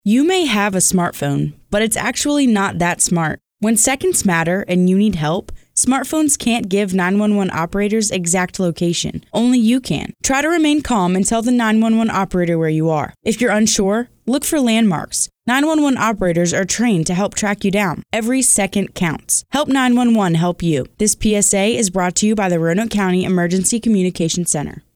Public Service Announcements (PSA)